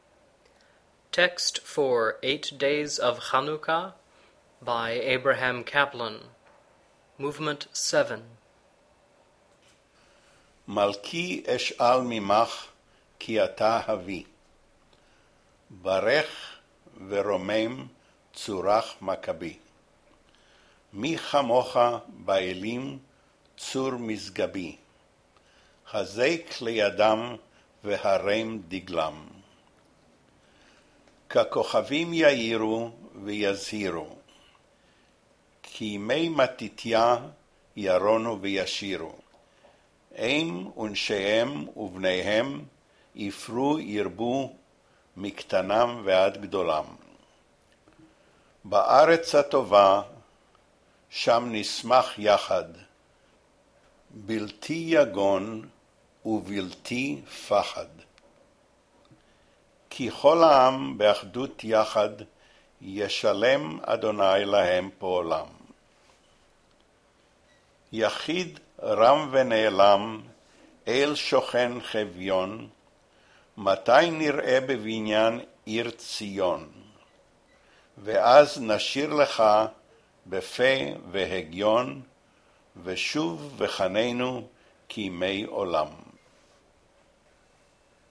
These audio files are intended for singers not familiar with diction rules for each work's language.